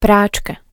pračka.wav